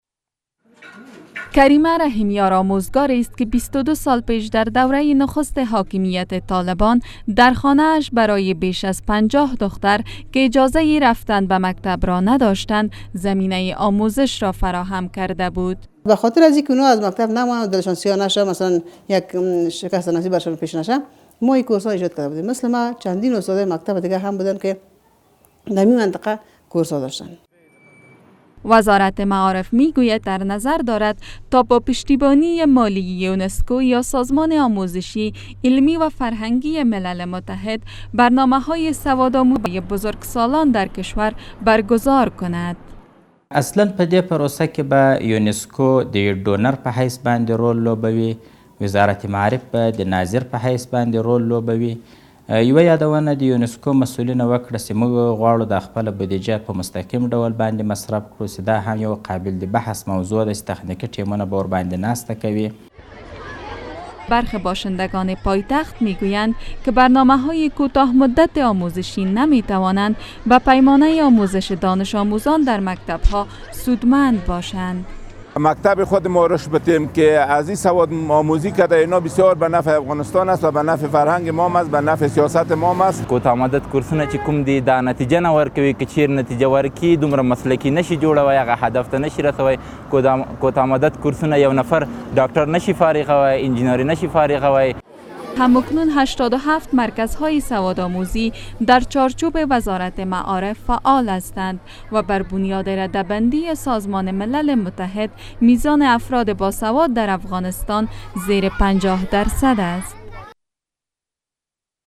تعدادی از شهروندان افغانستان